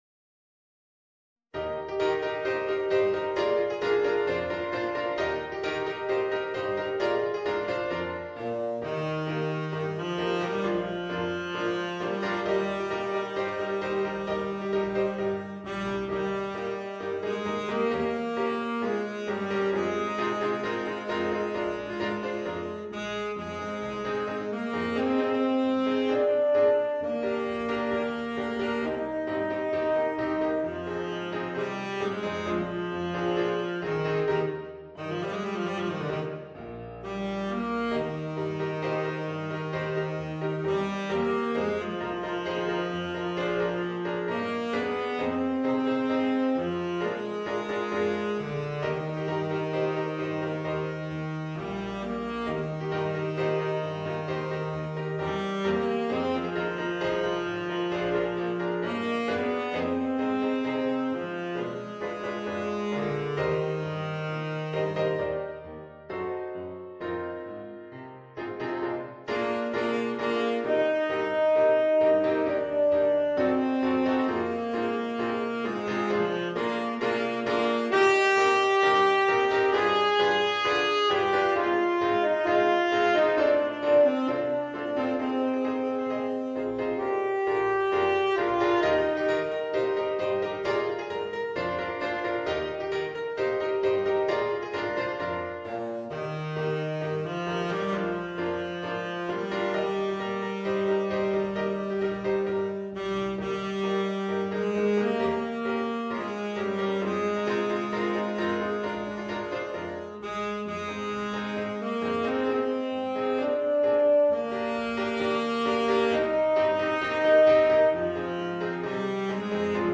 Voicing: Tenor Saxophone and Piano